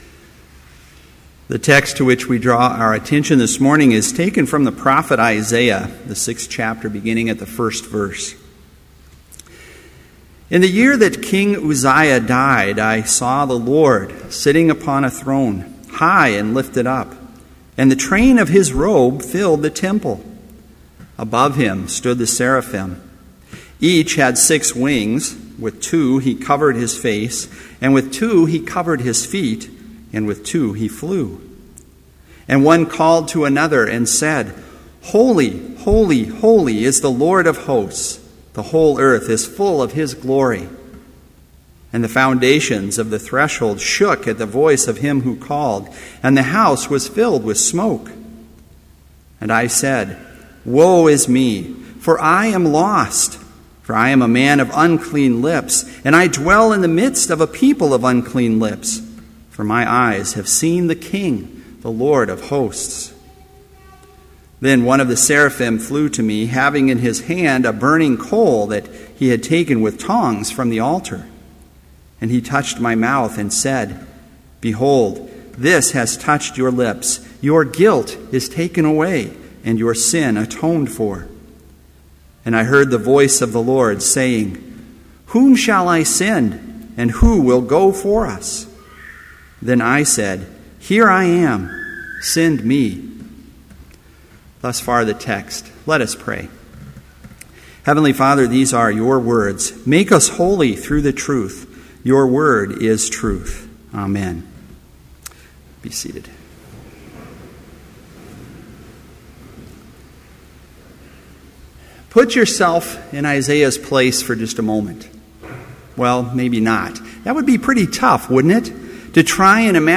Chapel worship service held on October 29, 2013, BLC Trinity Chapel, Mankato, Minnesota
Complete service audio for Chapel - October 29, 2013